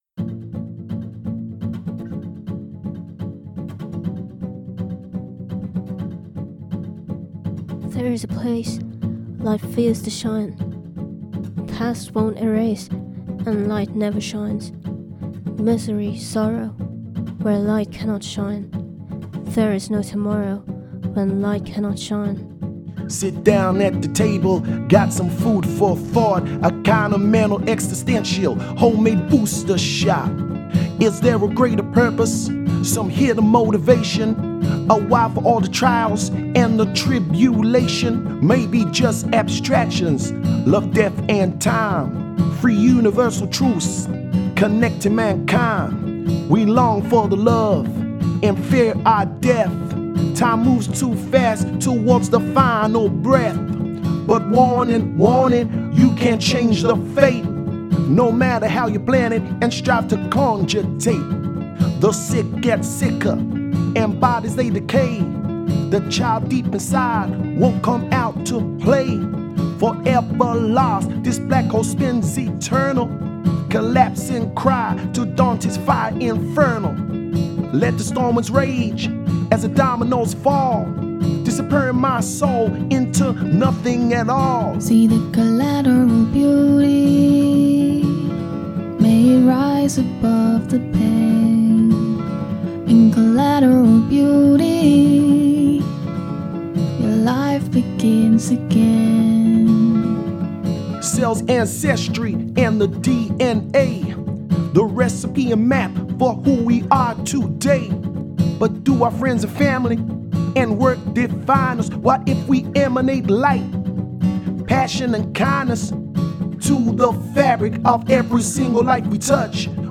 guitar
The instrumentals are so low compared to the vocals.